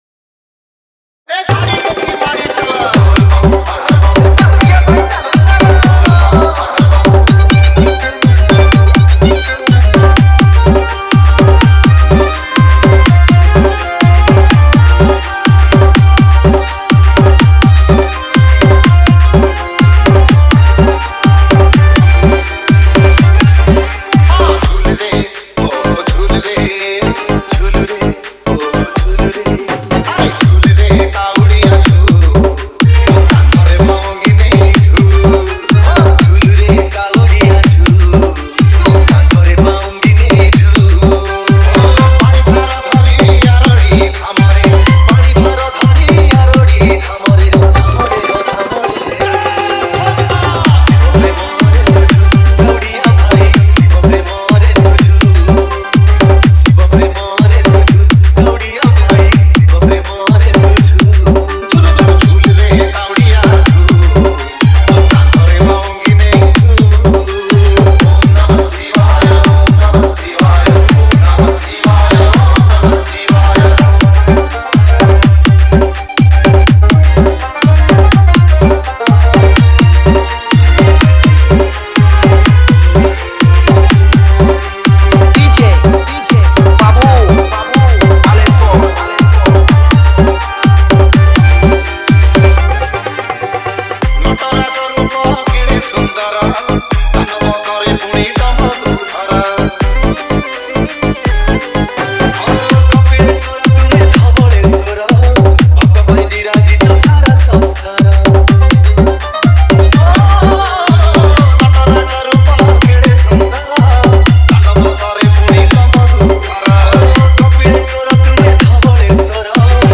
Category:  Odia Bhajan Dj 2022